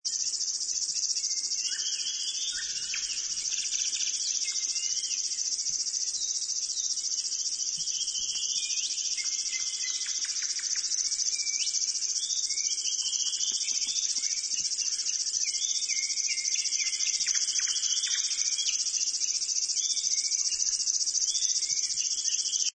Viitasirkkalintu
Helposti tunnettava laulu on jatkuvaa hepokattimaista tikitystä (Kuvattu 6.6.2002, äänitetty Laatokan Karjalassa).